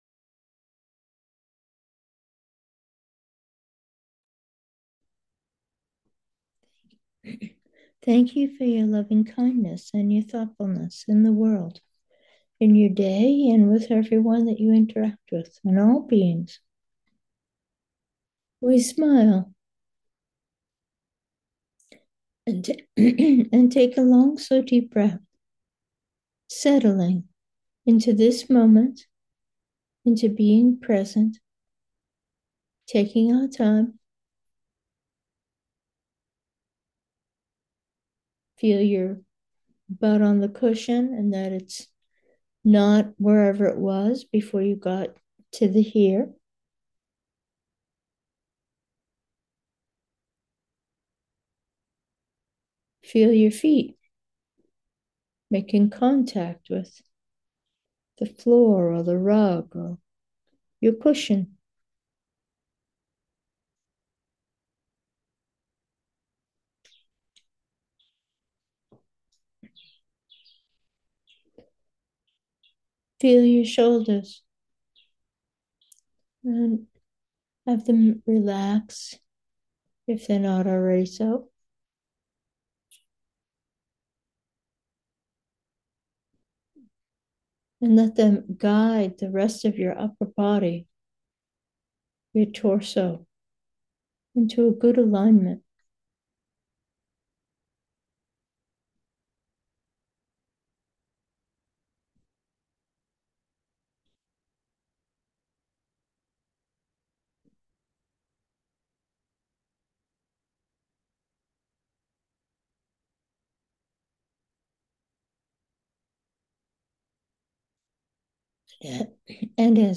Meditation: equanimity 3, ah